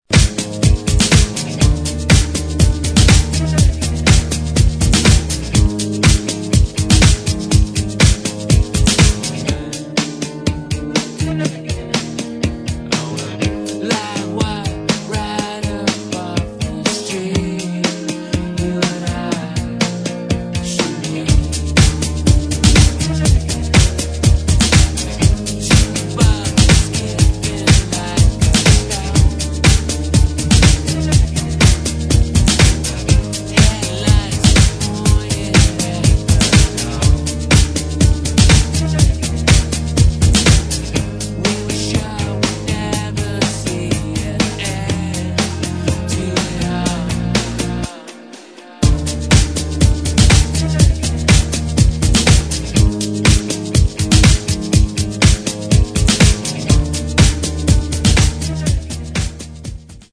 [ HOUSE / EDIT ]